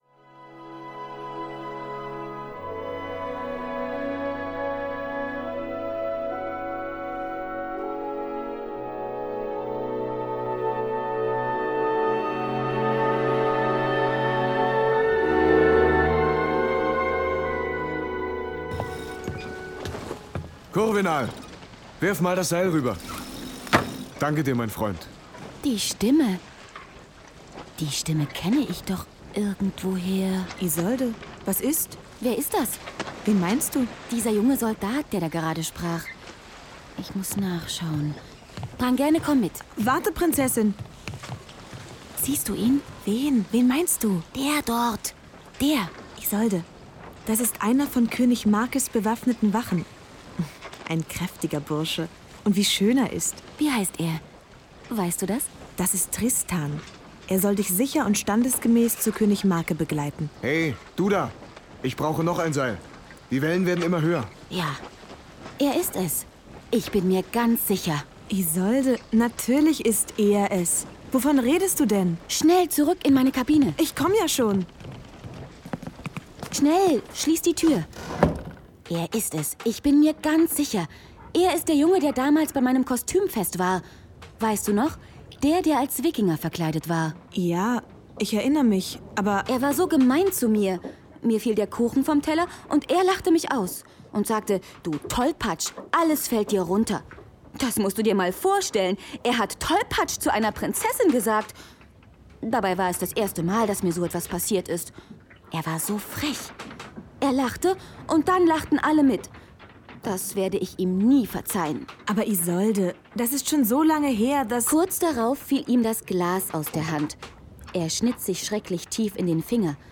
Hörspiel mit Opernmusik